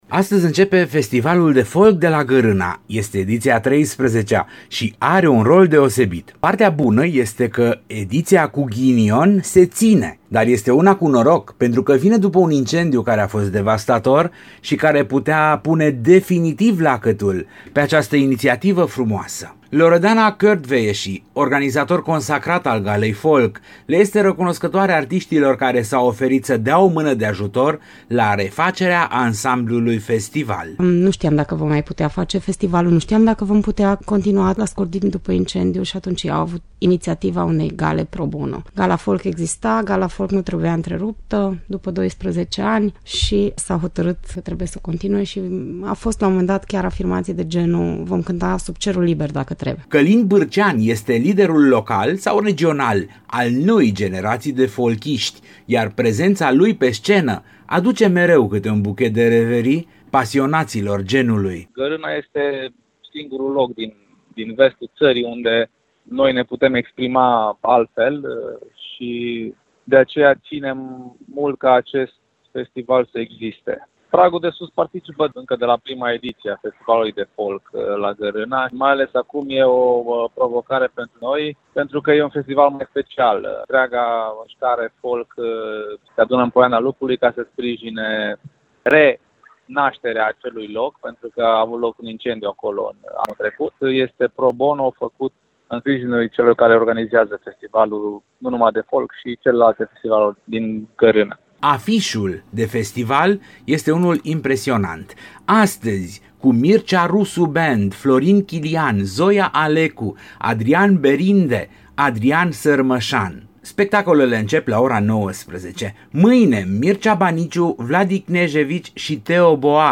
Reportaj_Deschidere_FestivalFolk_editia13.mp3